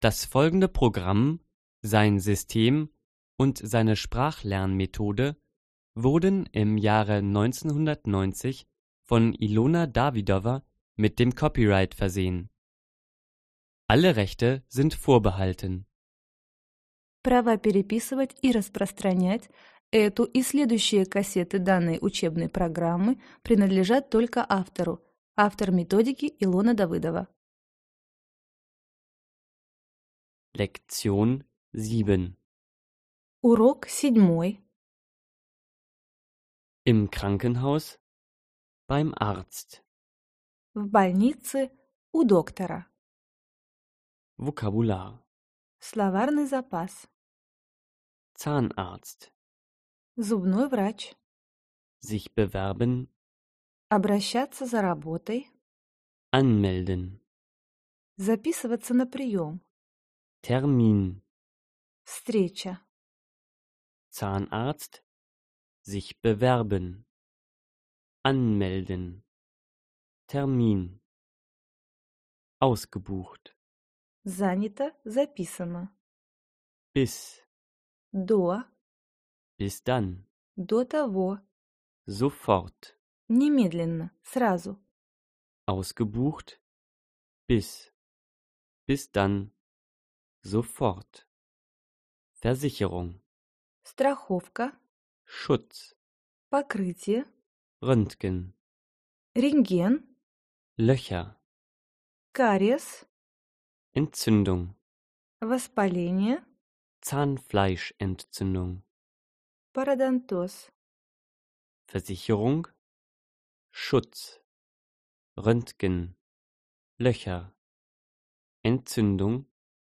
Аудиокнига Разговорно-бытовой немецкий язык. Диск 7 | Библиотека аудиокниг